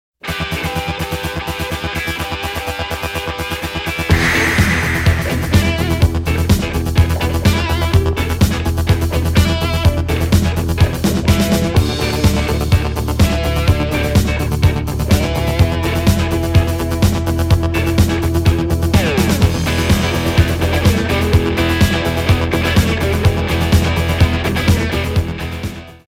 • Качество: 320, Stereo
ритмичные
громкие
заводные
dance
без слов
инструментальные
электрогитара
Rock